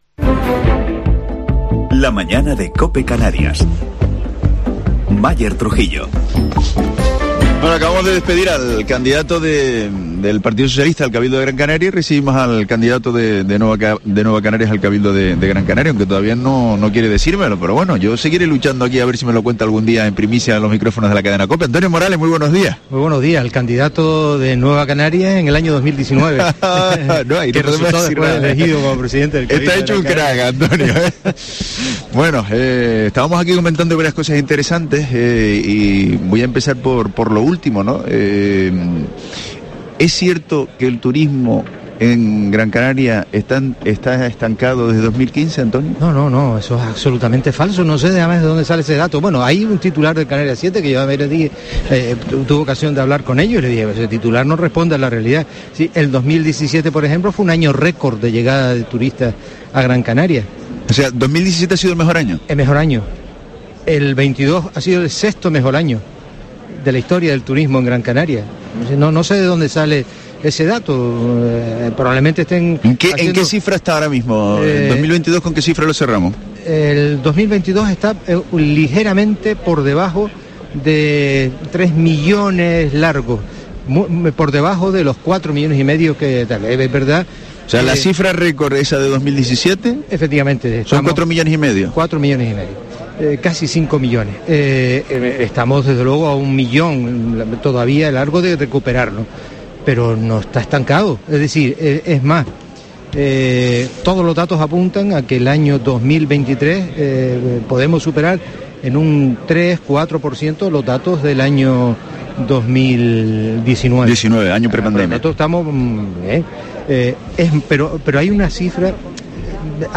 Entrevista a Antonio Morales, presidente del cabildo de Gran Canaria